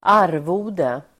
Uttal: [²'ar:vo:de]